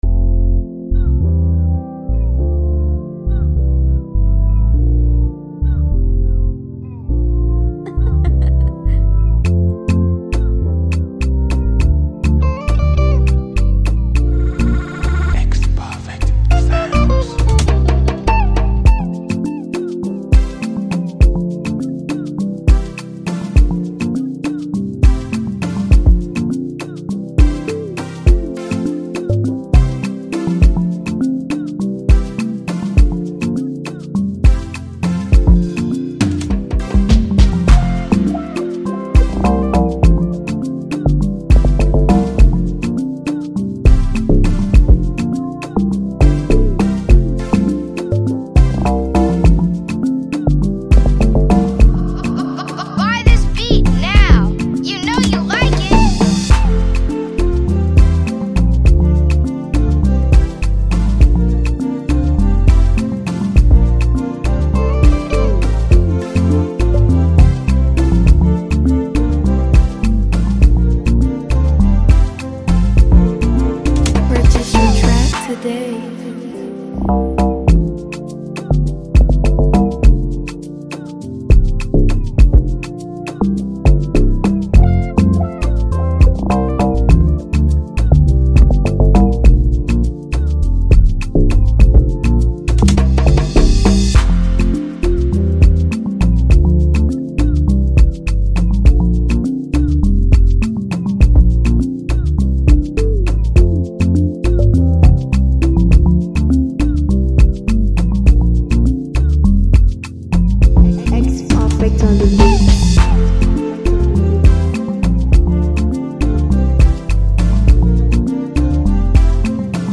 free beat instrumental
smooth and emotional FreeBeat instrumental
Afro-fusion instrumental